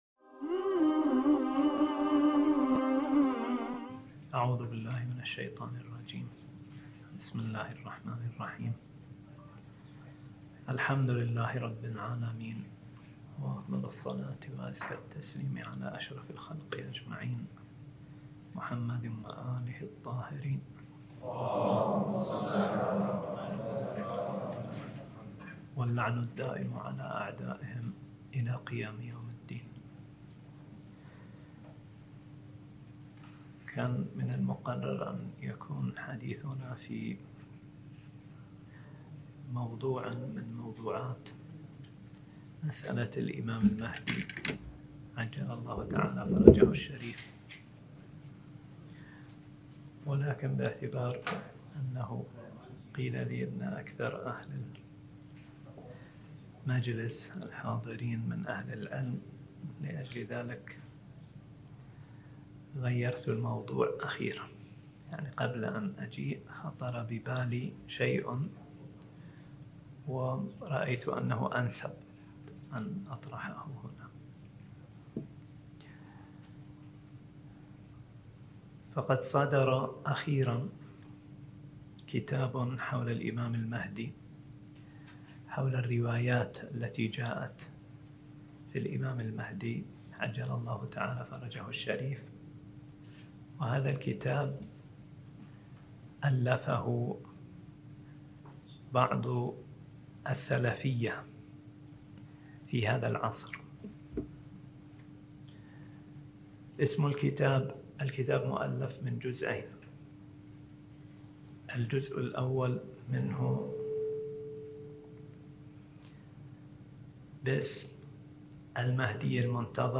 الدورة المهدوية المكثفة ( الأولى ) _ المحاضرة الثامنة عشرة